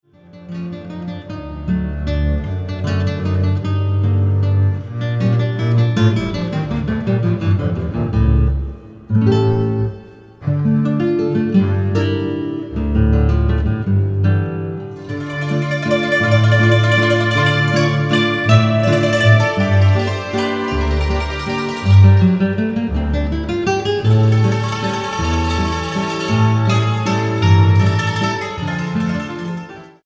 guitarra
contrabajo